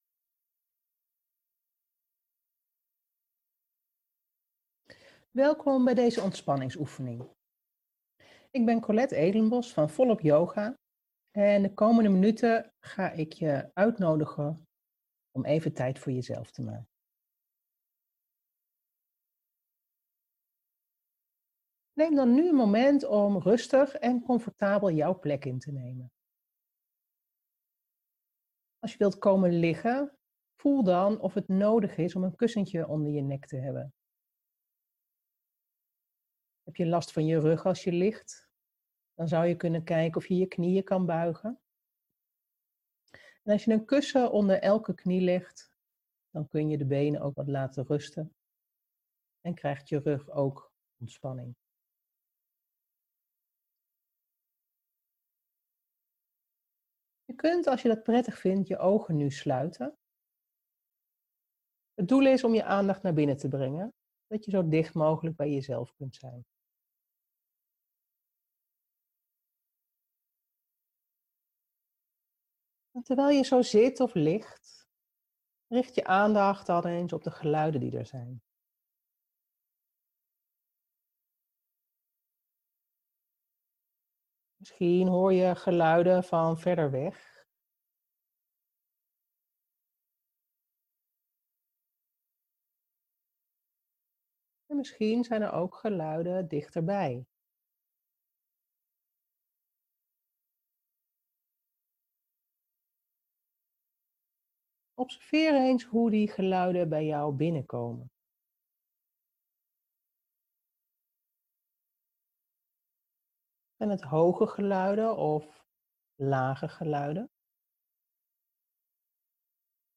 ontspanningsoefening
minicursus-meditatie-bij-les-3.mp3